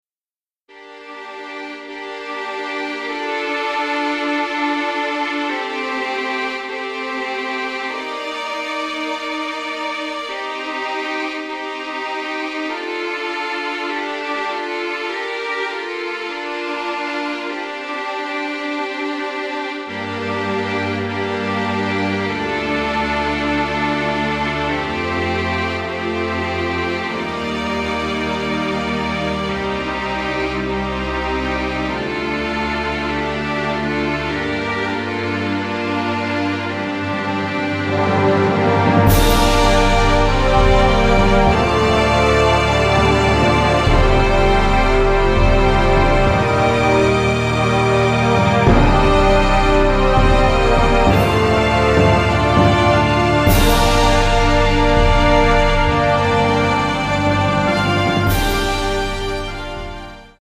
Track 1-11 ... リマスタリングver